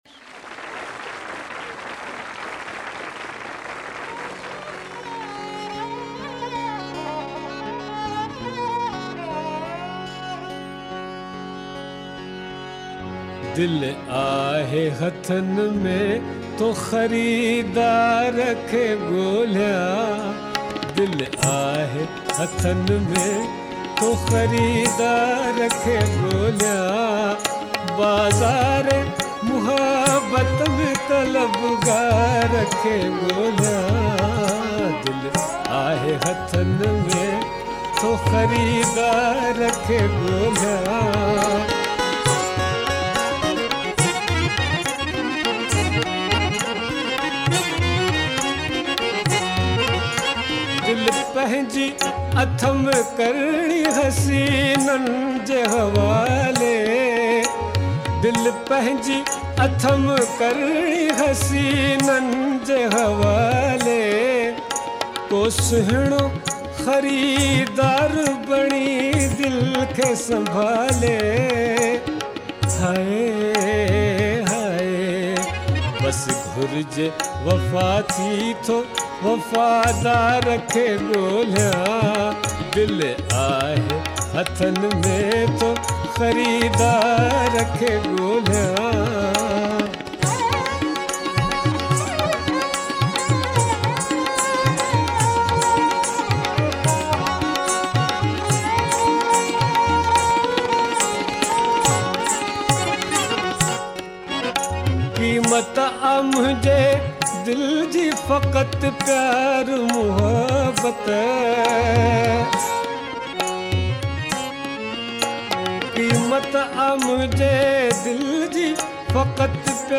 song
soothing voice